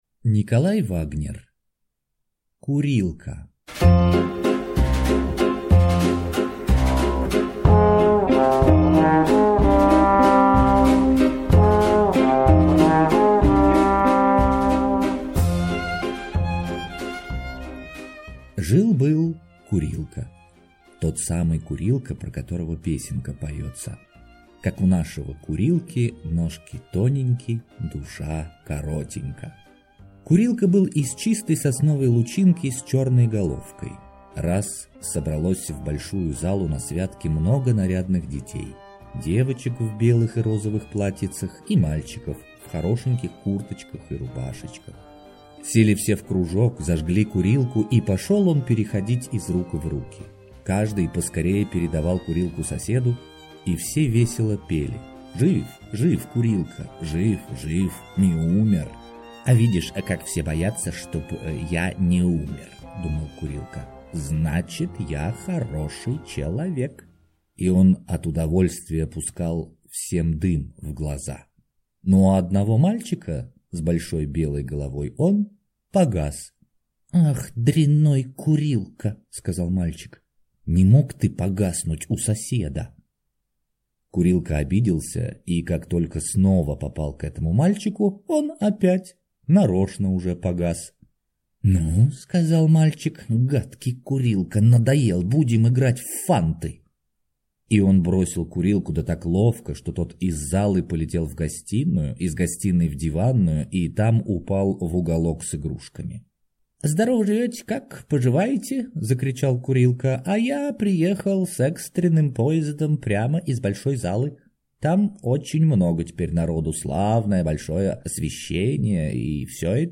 Аудиокнига Курилка | Библиотека аудиокниг
Прослушать и бесплатно скачать фрагмент аудиокниги